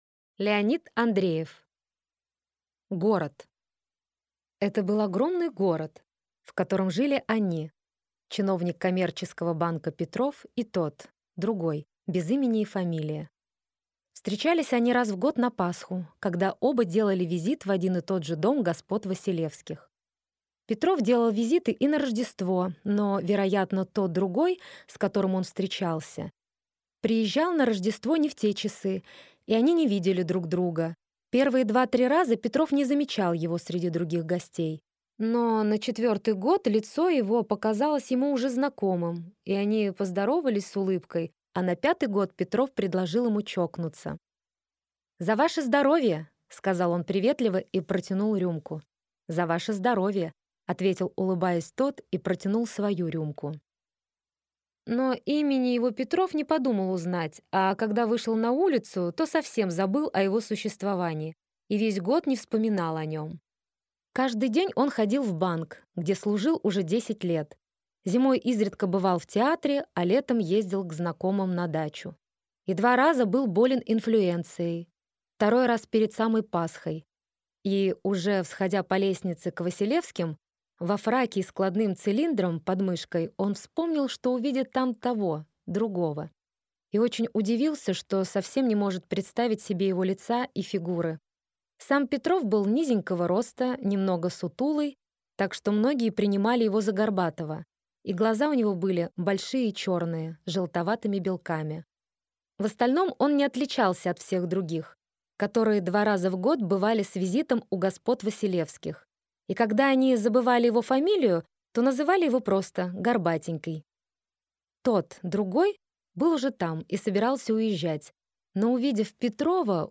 Аудиокнига Город | Библиотека аудиокниг